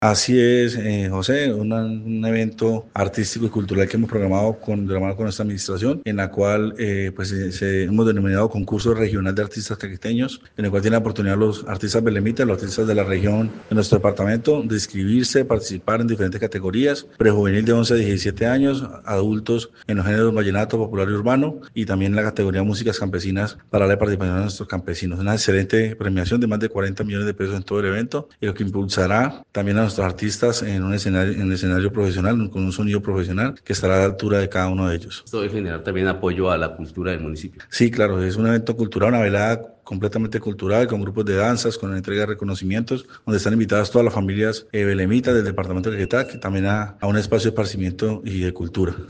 Wilmar Ballen Losada, alcalde del municipio de Belén de los Andaquíes, dijo que, se ha querido incentivar a los artistas del municipio y del departamento en categorías y géneros como vallenato, popular, urbano y campesina, apoyando la cultura local.